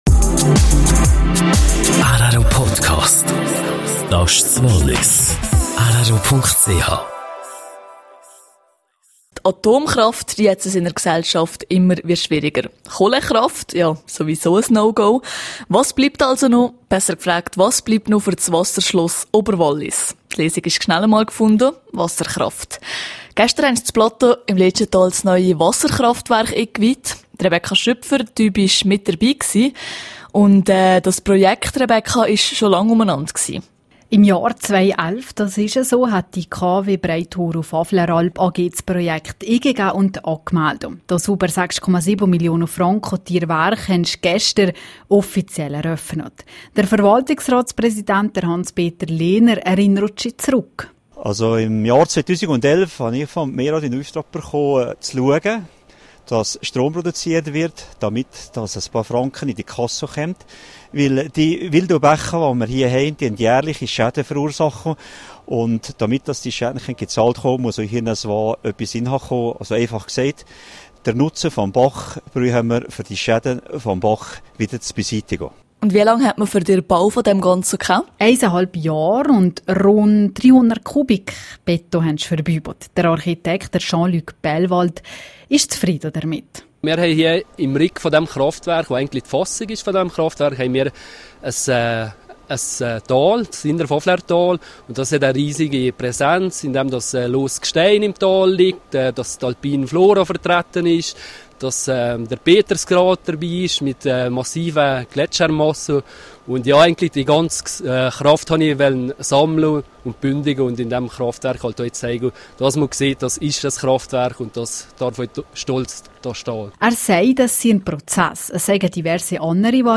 26880_News.mp3